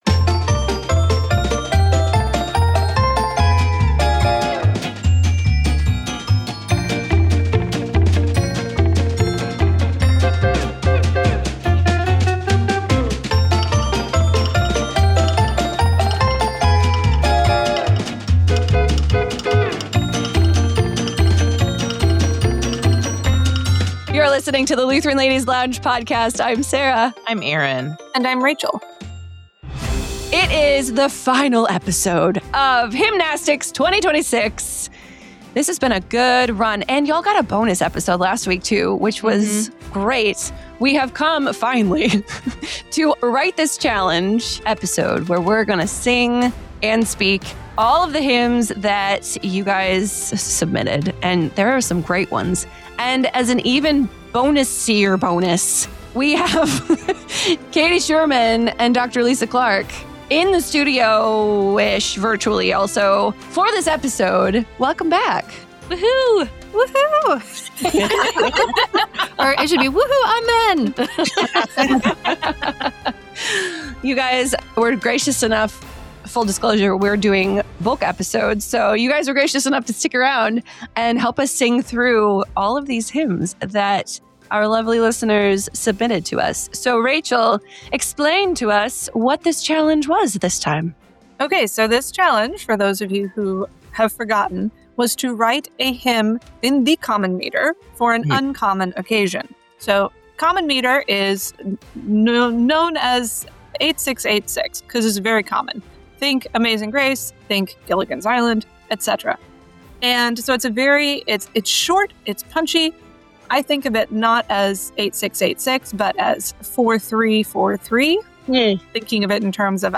Throughout this series, they’ll laugh, they’ll cry, they’ll sweat (sometimes literally), and above all, they’ll sing as they celebrate some of the greatest hymns and hymnwriters past, present, and even yet to come.